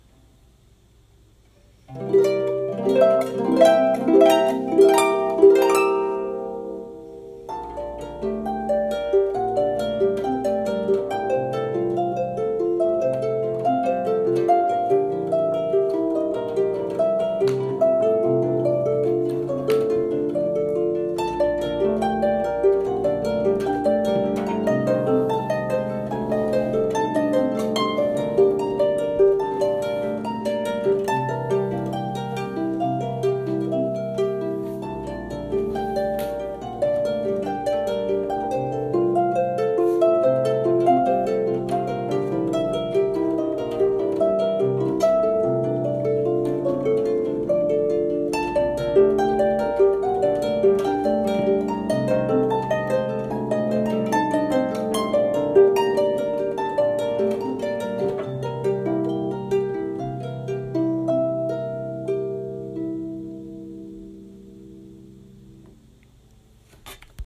Pedal Harp Solo